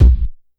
Kick_101.wav